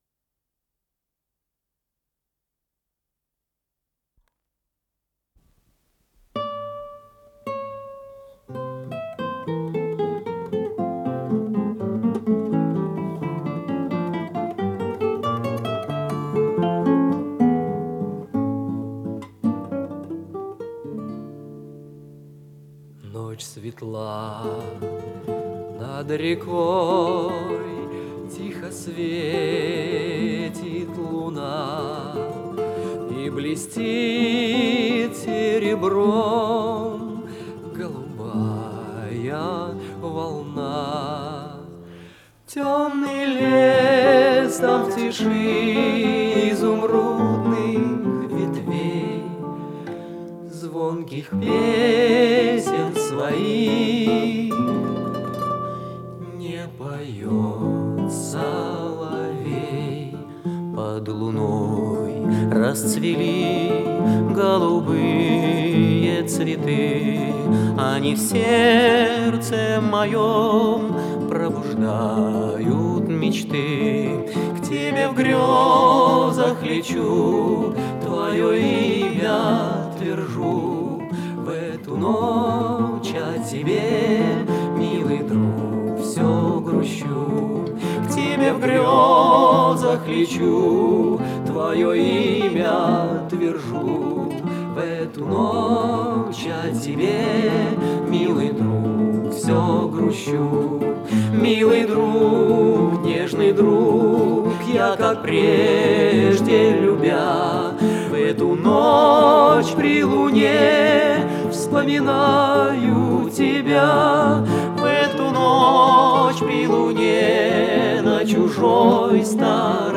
с профессиональной магнитной ленты
ИсполнителиМосковский квартет "Случайная встреча"
вокал
гитары
ВариантДубль моно